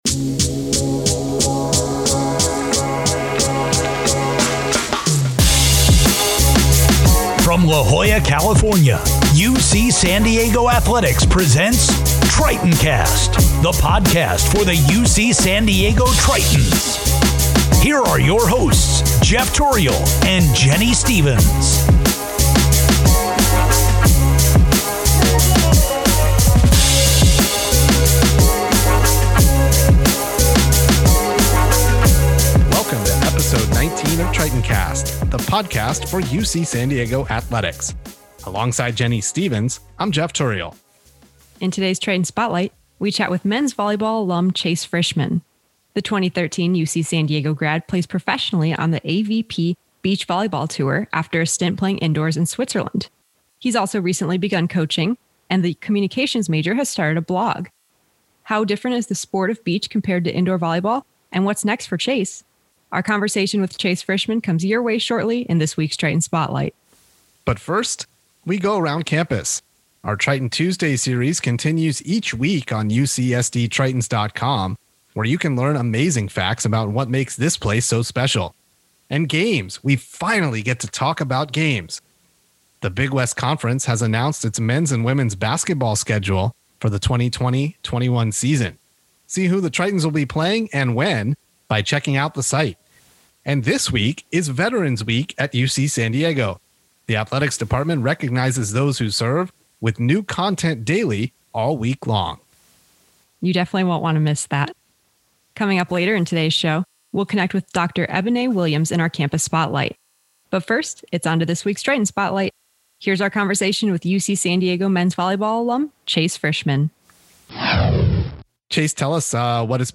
MEDIA • Tritoncast Interview